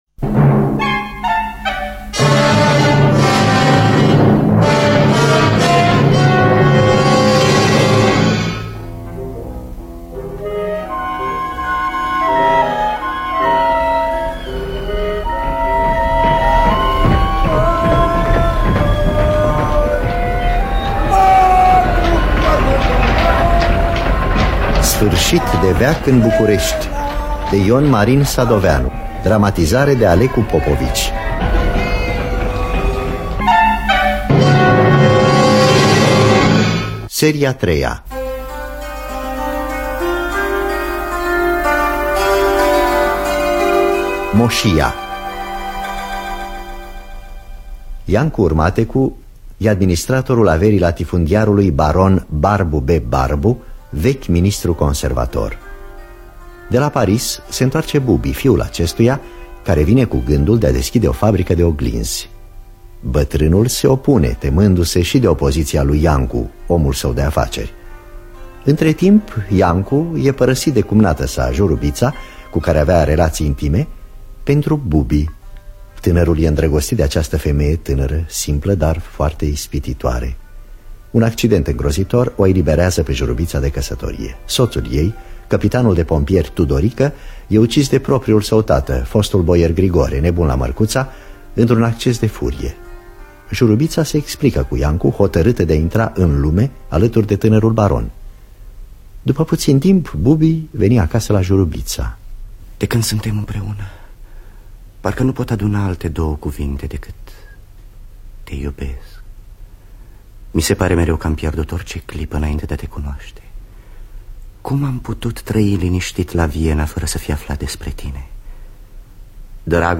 Dramatizarea radiofonică de Alecu Popovici.